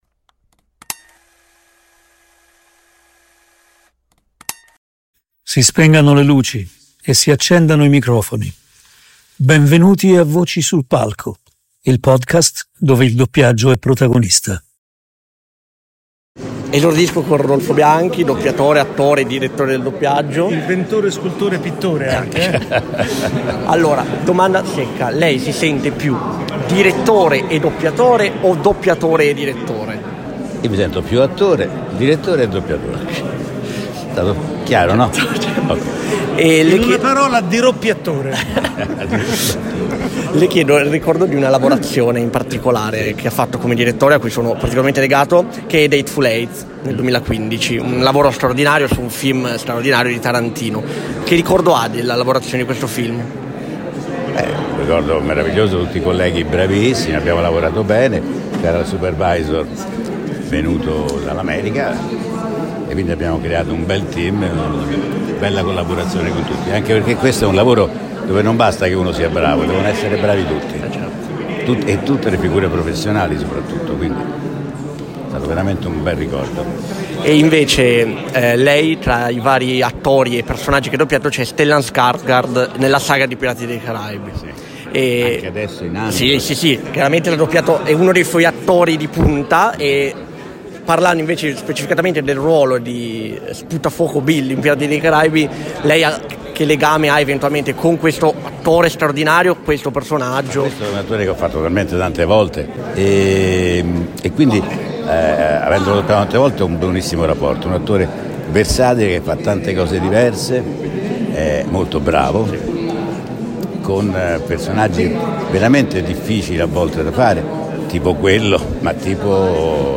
Interviste esclusive